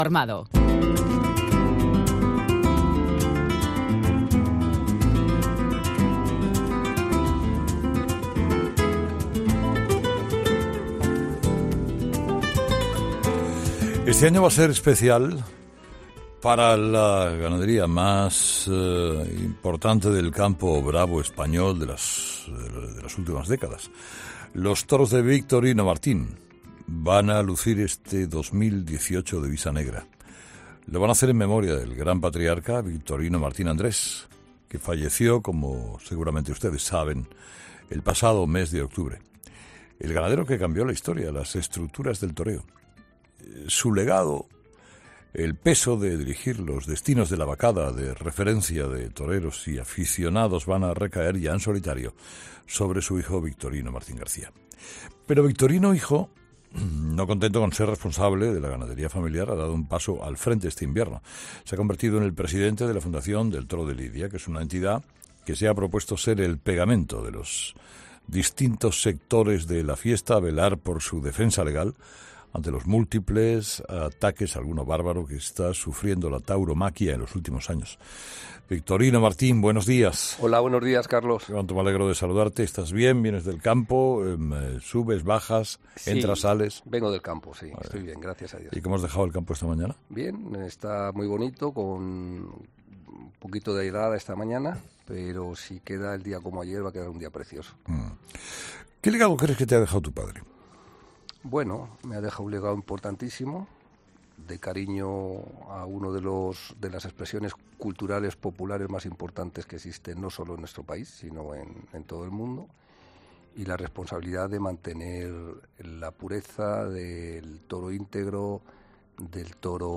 AUDIO: Victorino Martín, ganadero y presidente de la Fundación del Toro de Lidia, ha pasado por los micrófonos de Herrera en COPE